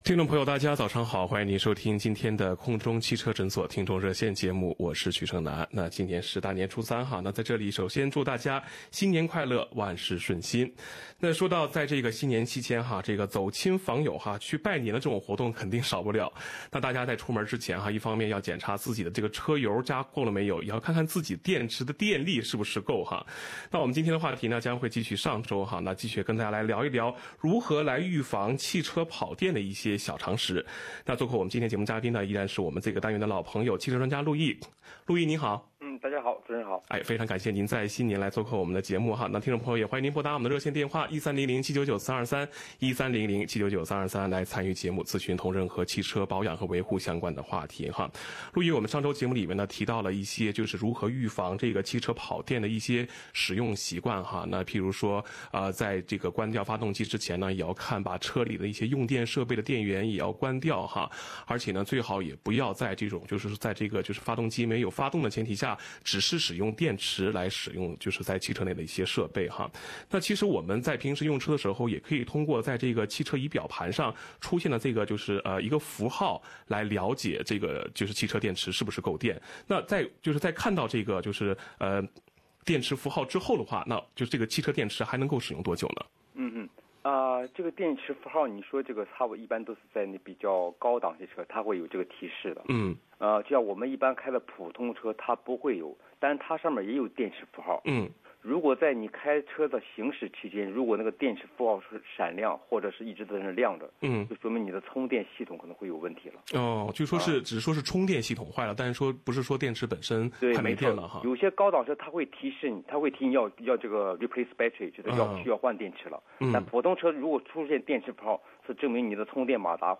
《空中汽车诊所-听众热线》节目